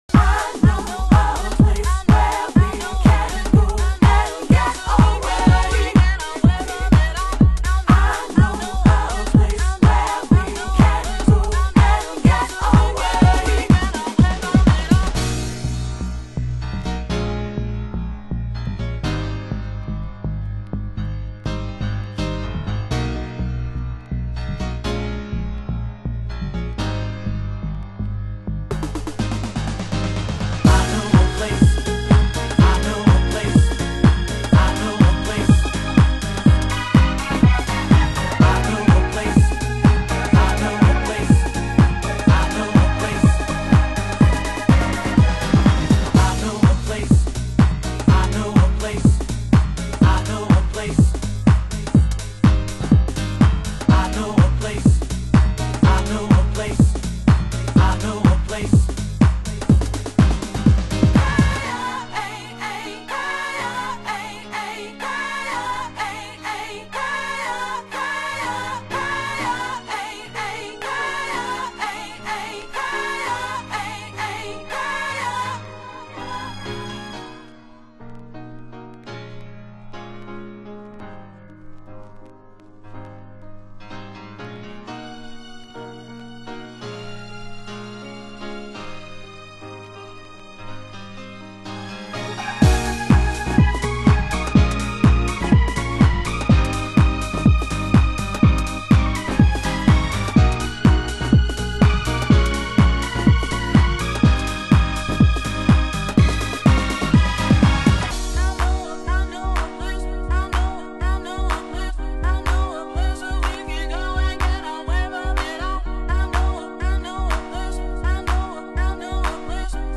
HOUSE MUSIC
盤質：少しチリパチノイズ有/B２にスレ有　　ジャケ：少しスレ有